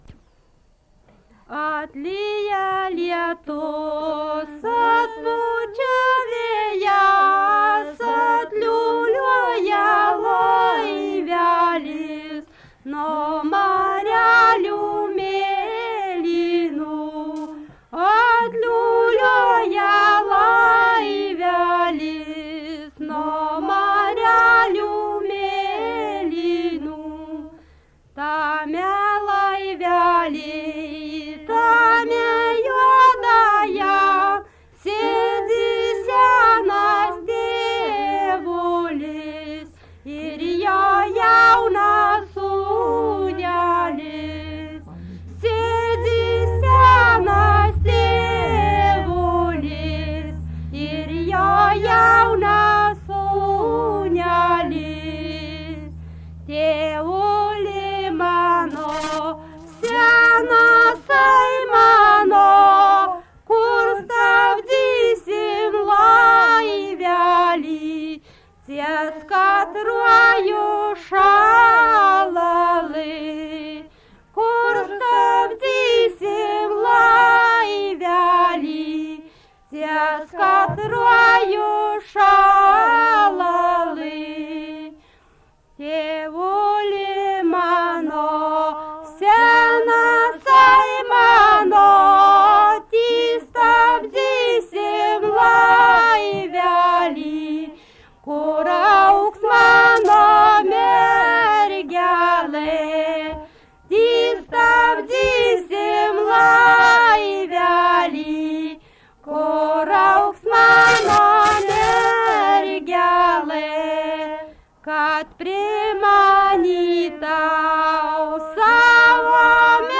daina
Erdvinė aprėptis Nemunaitis
Atlikimo pubūdis vokalinis